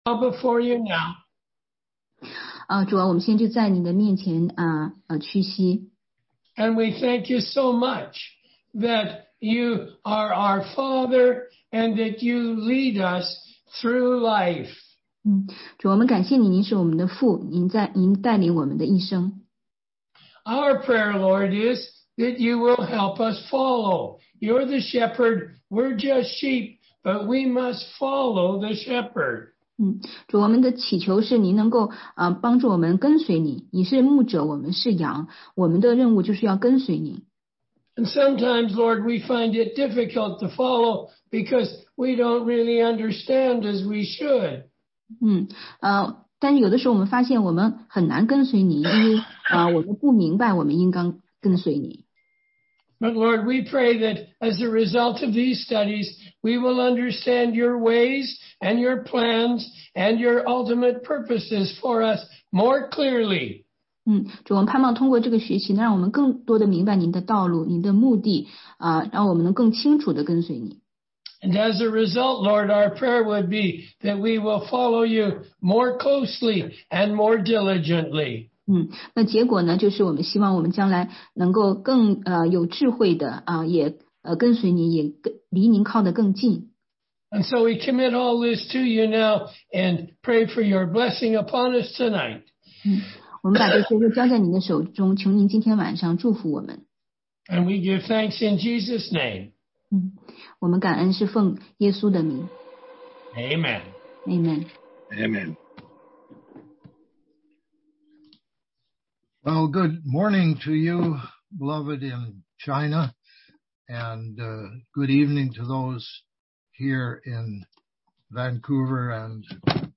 16街讲道录音 - 时代论系列之一：七个时代概述